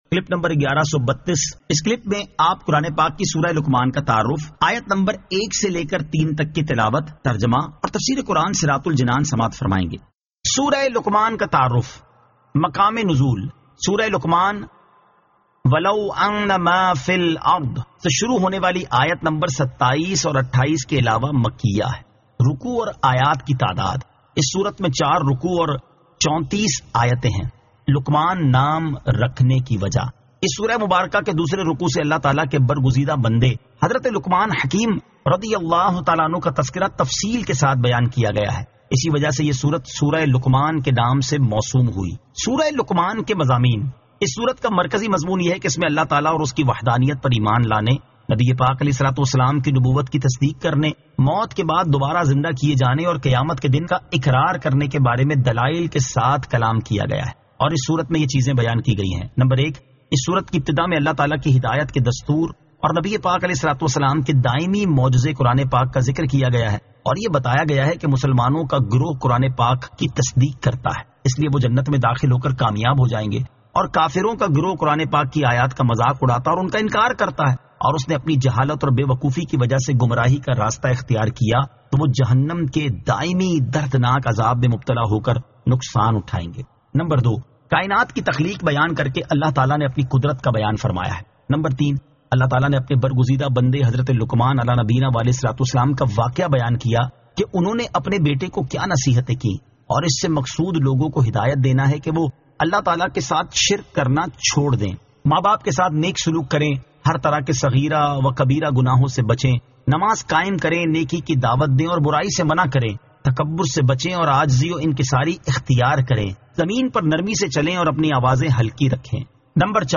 Surah Luqman 01 To 03 Tilawat , Tarjama , Tafseer